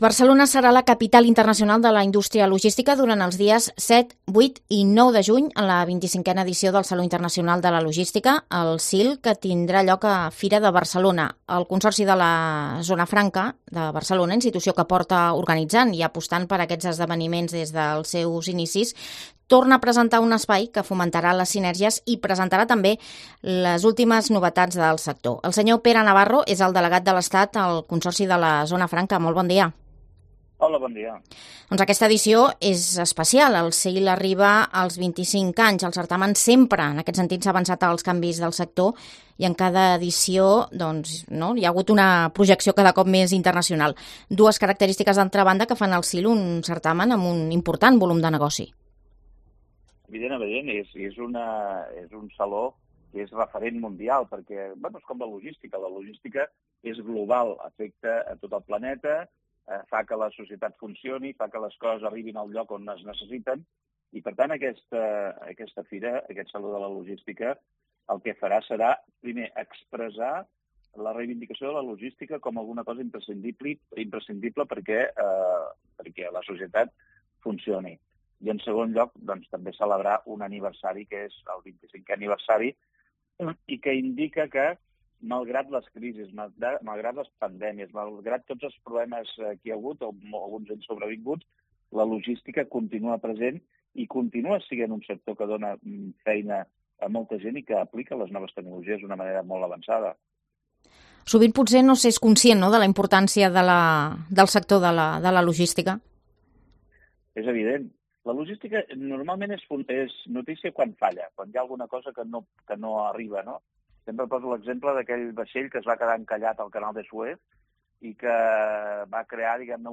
Pere Navarro, delegado especial del Estado en el Consorcio de la Zona Franca de Barcelona, habla, en una entrevista a COPE, de la edición número 25 del SIL: de internacionalización, de novedades y de la importancia del sector